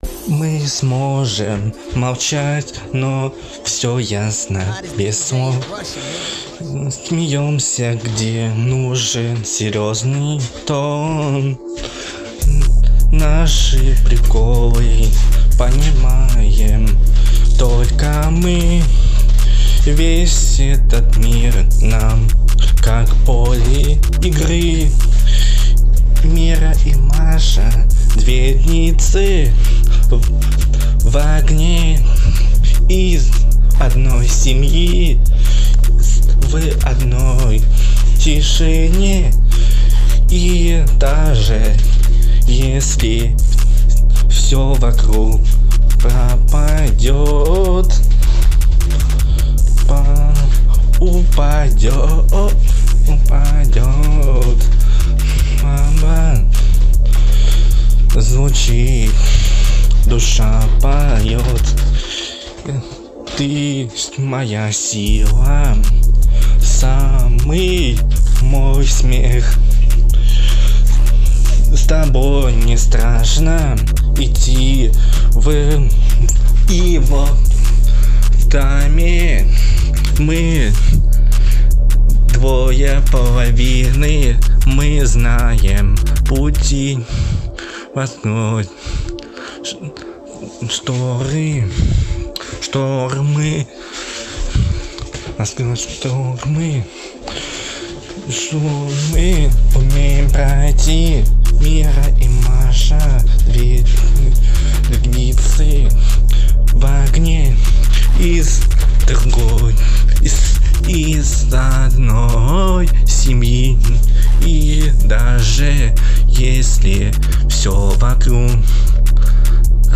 Жанр: рэп, хип-хоп